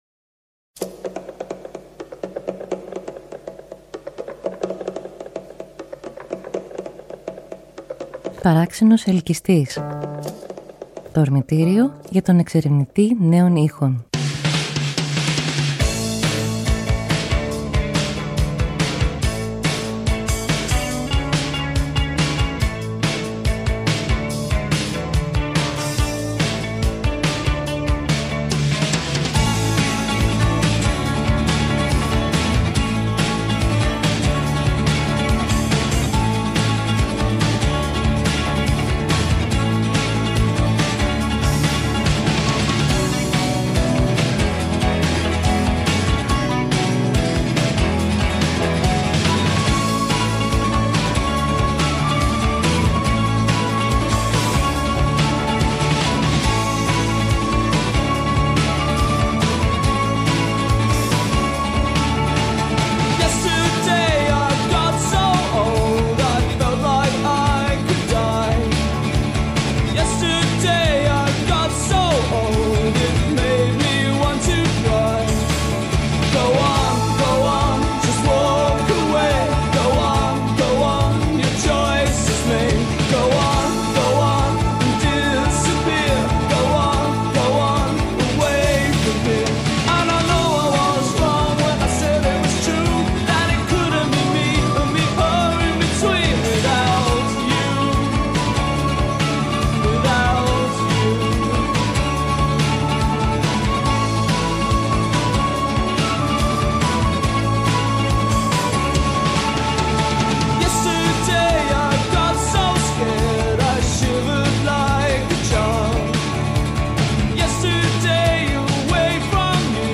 Περιλαμβάνει ηχητικά ποιήματα, ηχητικές κατασκευές «απροσεξίας» όπως τις χαρακτηρίζει, που προκύπτουν από κενά στη ροή του λόγου.